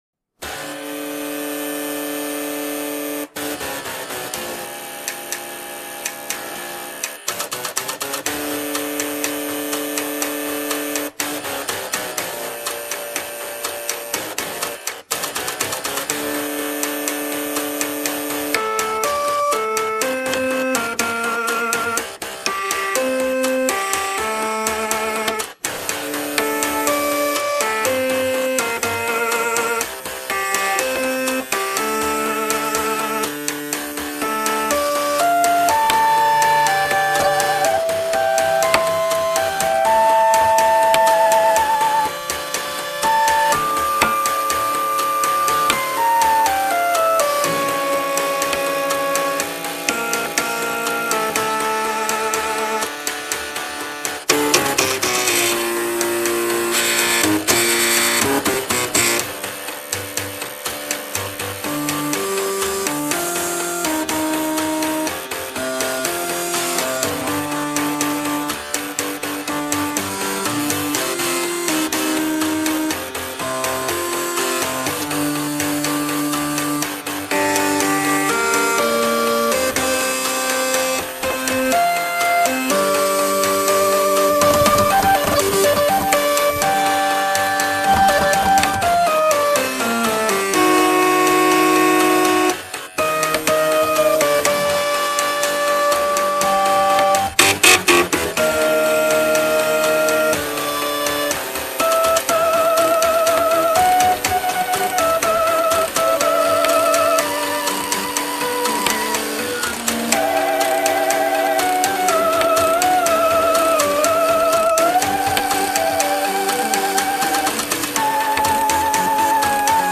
cover by computer hardware orchestra